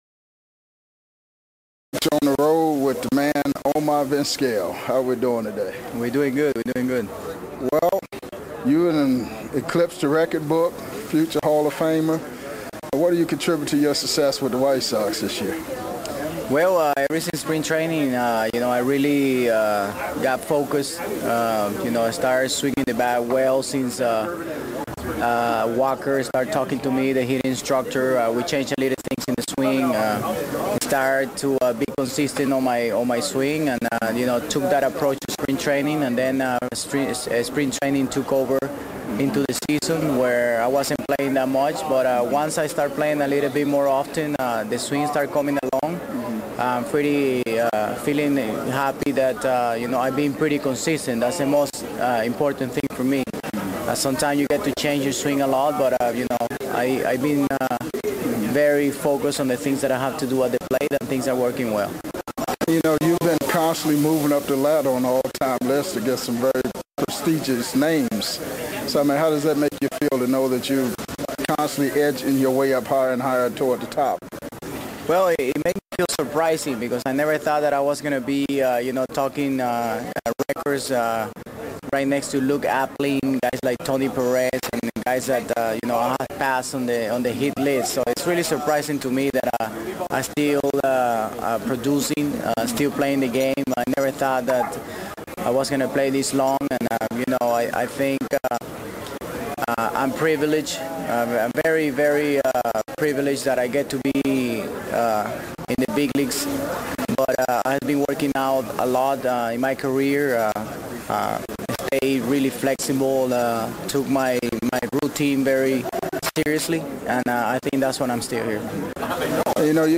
full uncut and unedited MLB interviews with past and present players